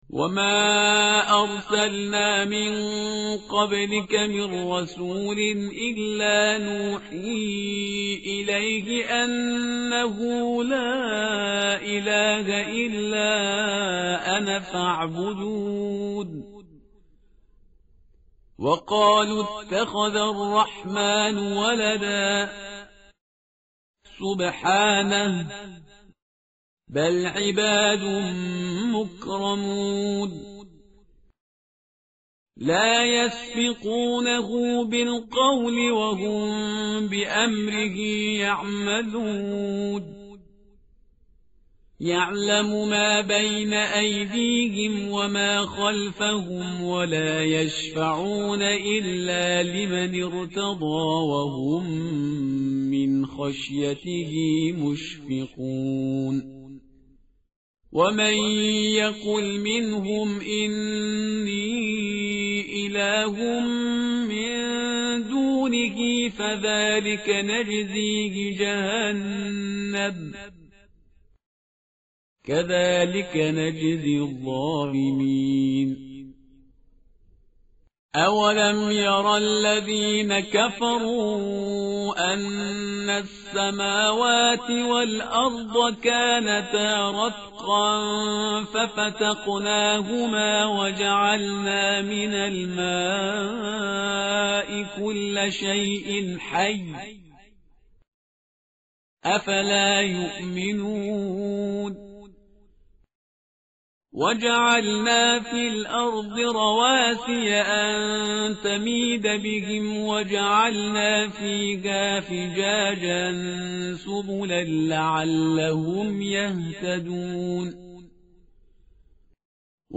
tartil_parhizgar_page_324.mp3